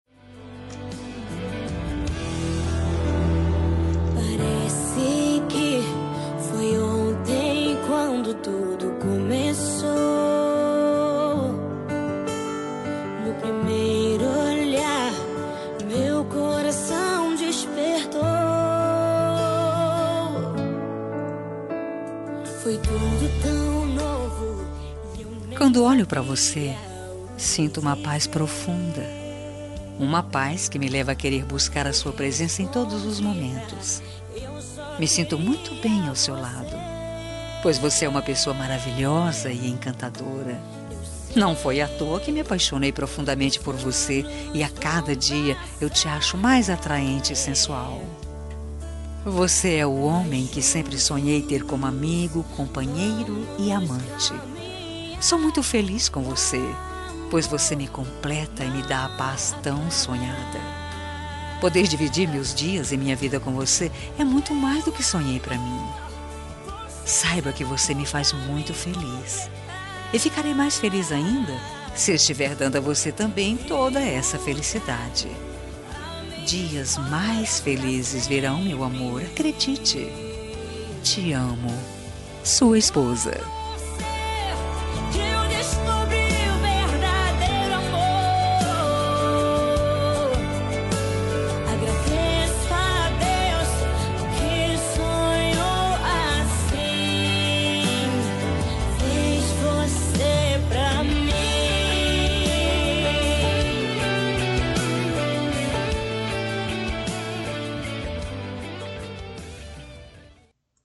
Telemensagem Romântica – Voz Feminina – Cód: 6730